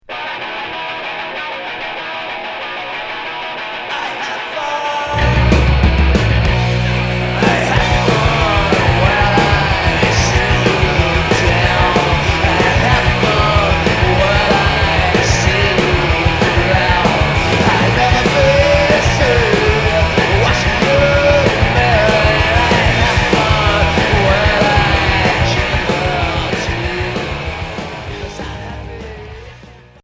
Rock noisy